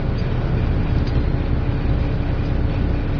machine_loop2.wav